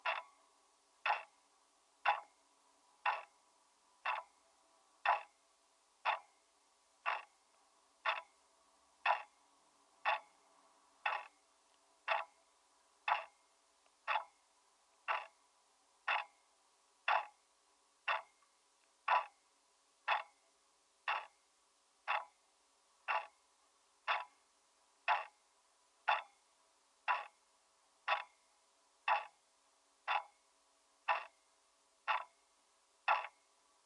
墙上的时钟滴答声录音
描述：墙上的时钟滴答声录音，完美的循环。 使用Zoom H2录音。使用 Audacity 进行编辑。
标签： 挂钟 滴答声 滴答 发条装置 循环 时间 塑料 滴答滴答 时钟
声道立体声